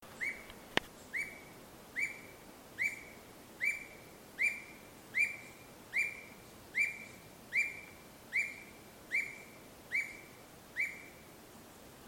Alma-de-gato (Piaya cayana)
Nome em Inglês: Common Squirrel Cuckoo
Fase da vida: Adulto
Localidade ou área protegida: Parque Provincial Cruce Caballero
Condição: Selvagem
Certeza: Observado, Gravado Vocal